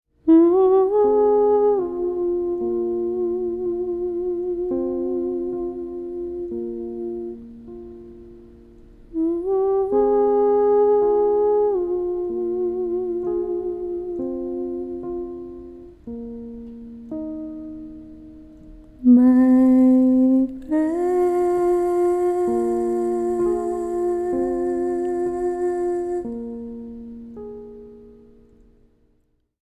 Healing Sounds
Piano, voice